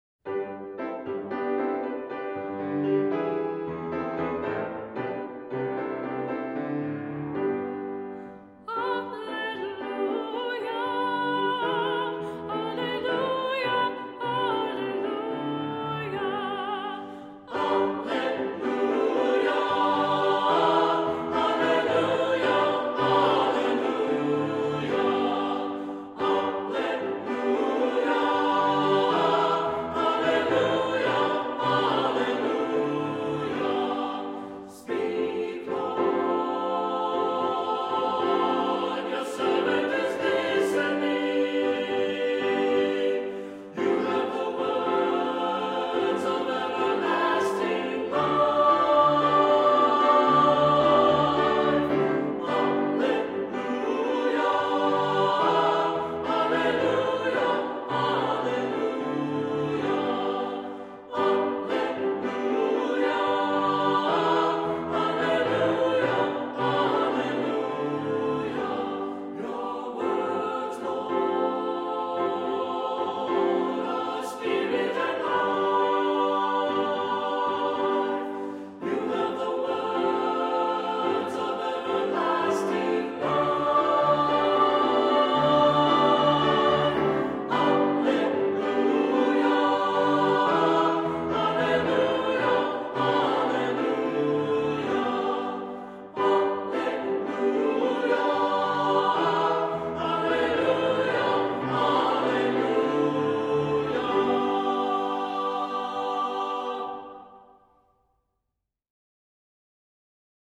Voicing: SAT; SAB; Assembly